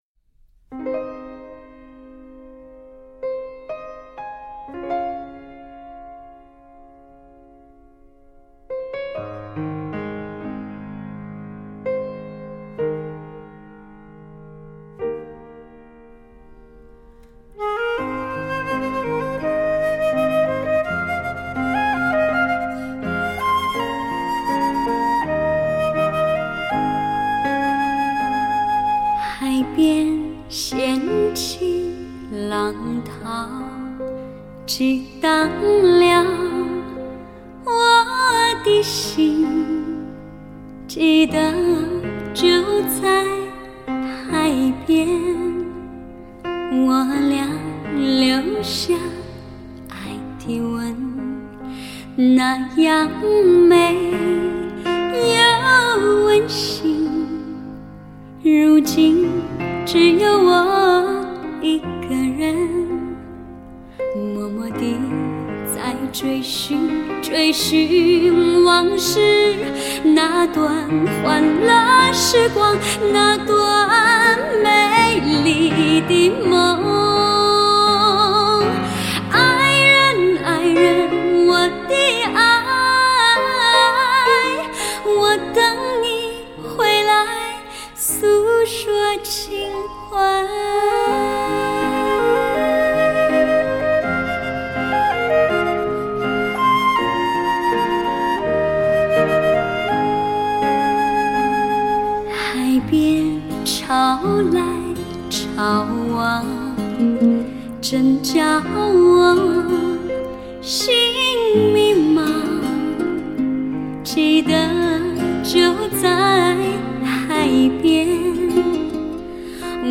声线成熟动人
其音质干静、技巧纯熟